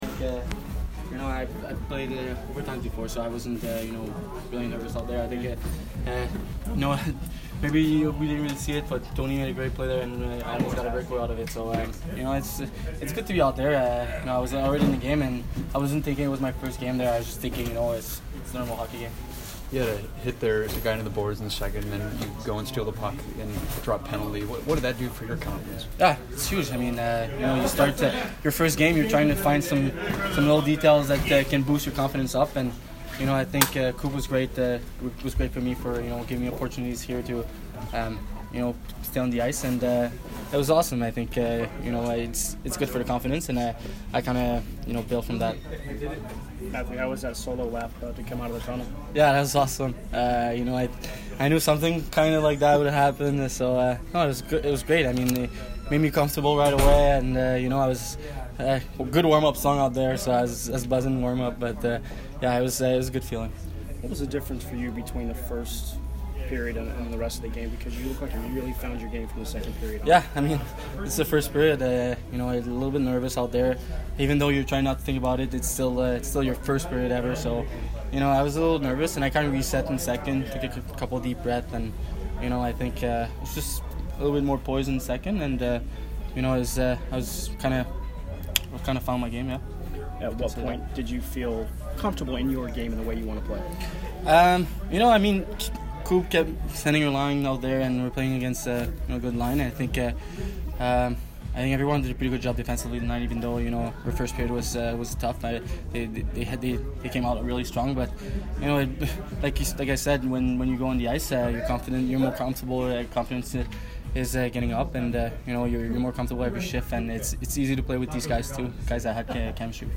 Mathieu Joseph post game 10/6